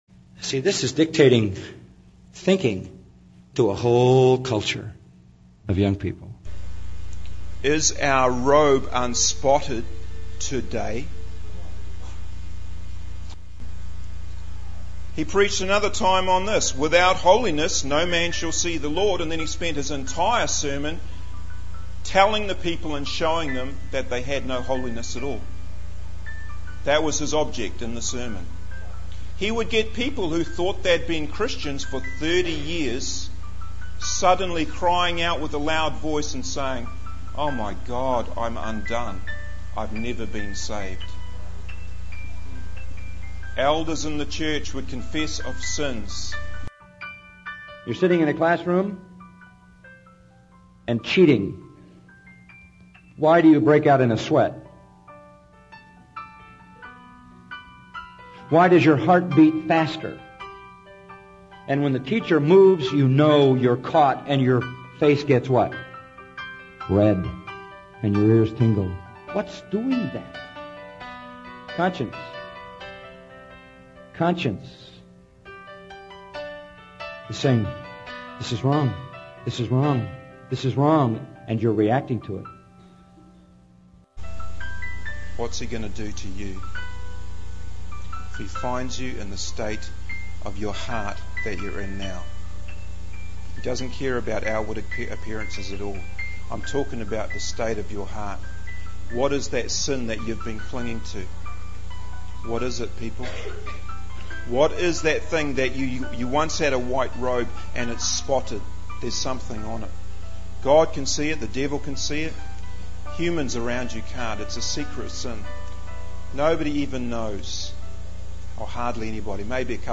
In this sermon, the preacher addresses the issue of why people often fall short in their spiritual journey and fail to overcome sin.